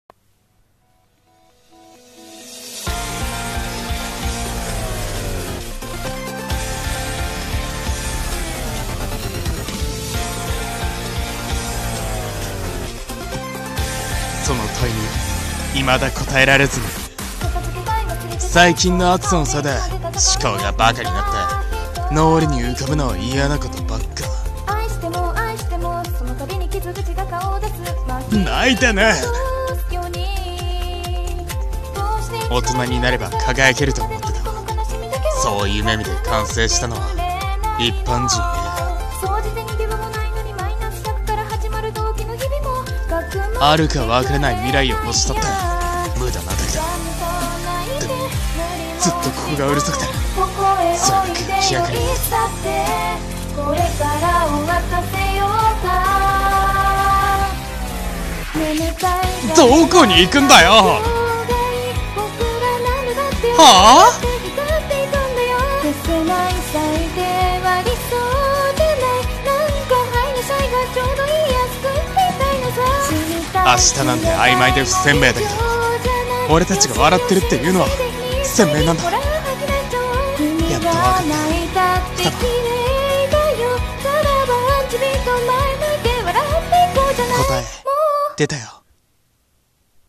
【二人声劇】未来繫フロントライン